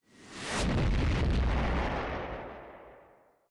051-Explosion04.opus